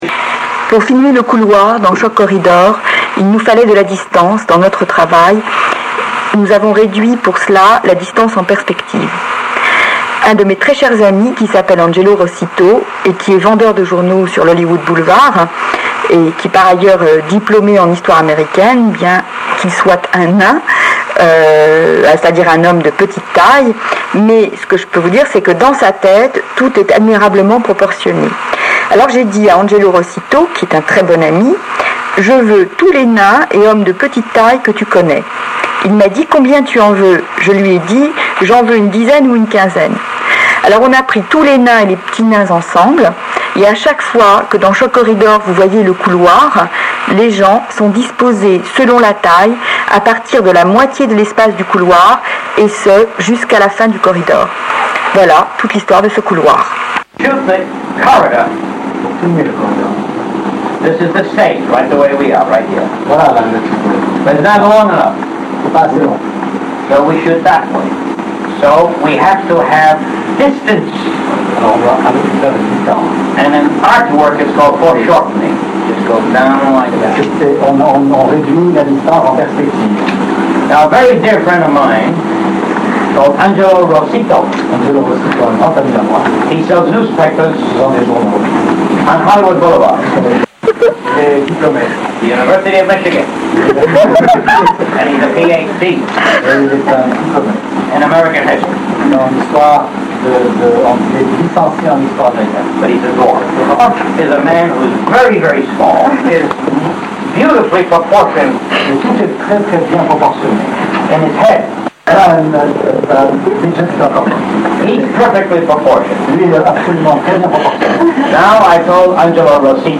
Samuel Fuller était venu à l’Action Christine nous parler du tournage de Shock Corridor. Je l’ai enregistré mais la prise son n’est pas très bonne et la bande abîmée.
Jouez avec les volumes du son : il est trop fort au début, pas assez par la suite !!!